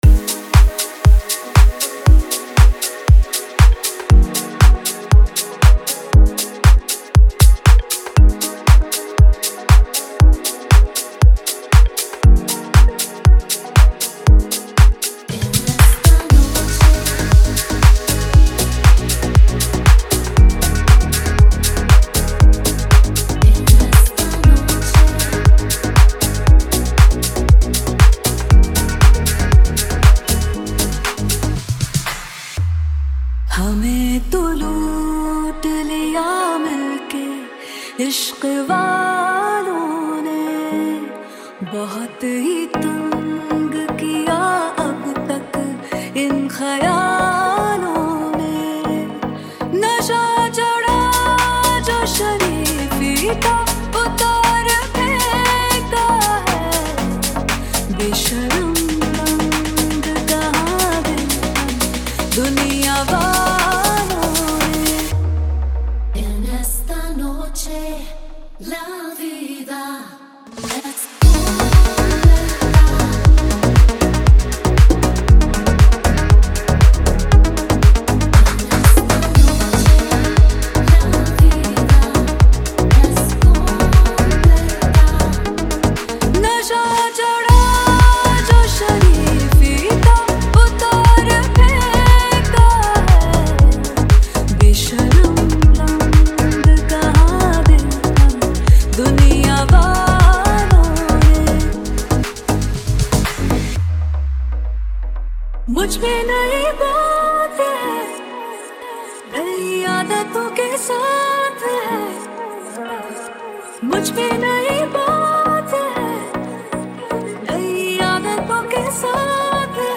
Bollywood Deep House
Bollywood DJ Remix Songs